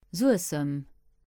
Sanem (Luxembourgish: Suessem [ˈzuəsəm]
Lb-Suessem.ogg.mp3